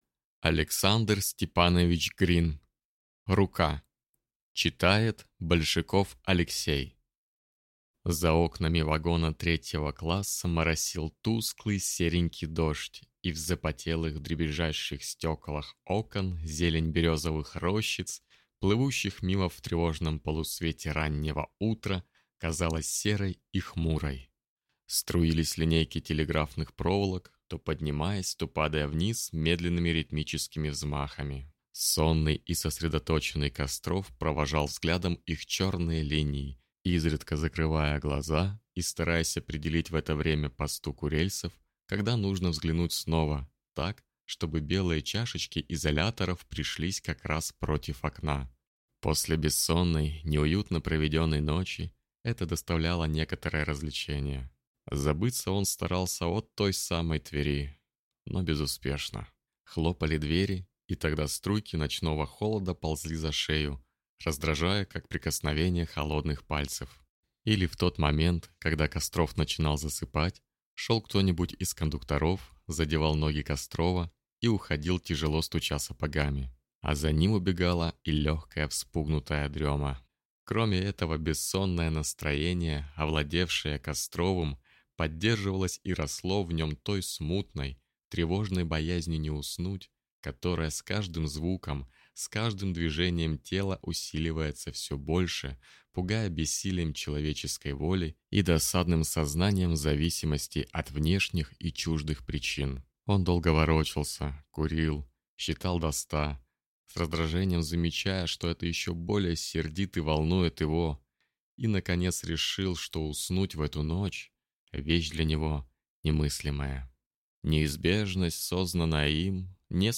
Аудиокнига Рука | Библиотека аудиокниг